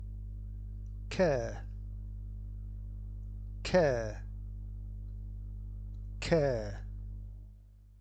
Don’t say: Cairy. Say: K-air.
The ending is the same as in hair.